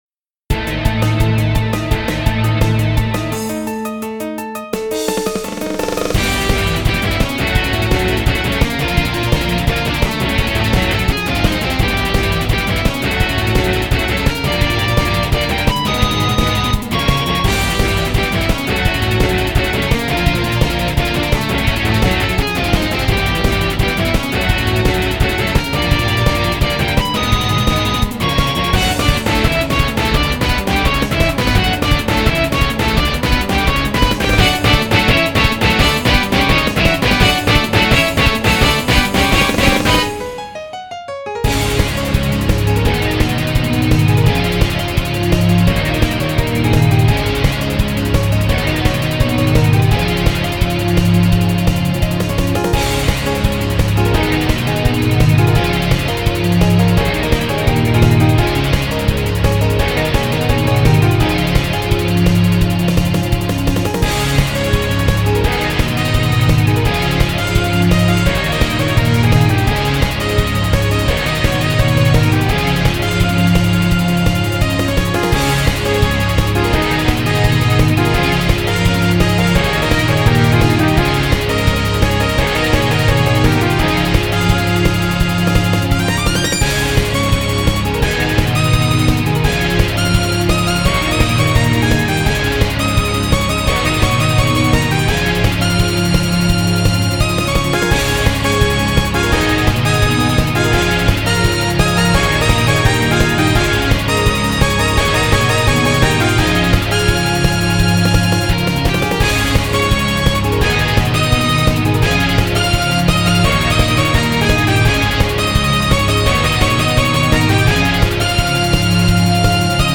改めて聴くと結構音が濁ってるなぁ…。メロディは好きなんだけどね。